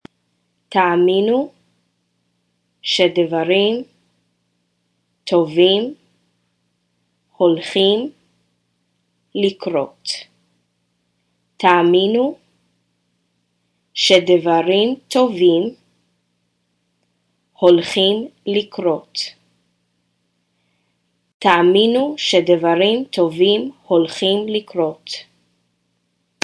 Listen to me read the quote here 3x